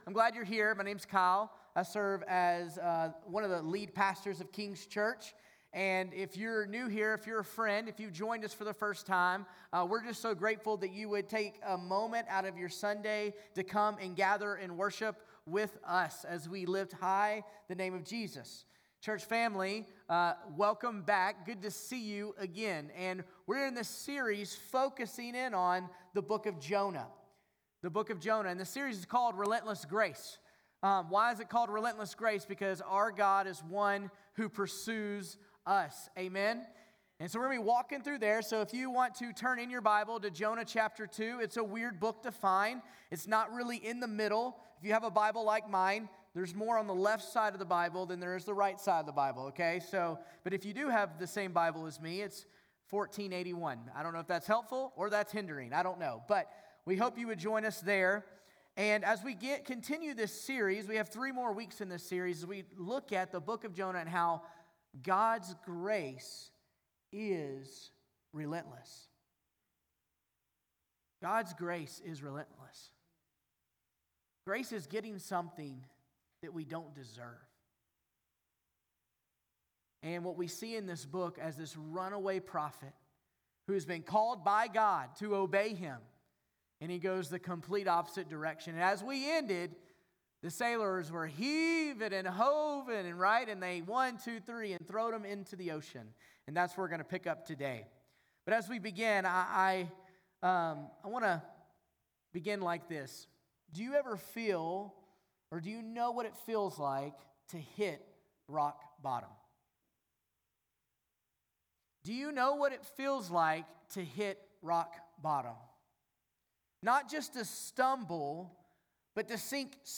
August 10 Sermon.mp3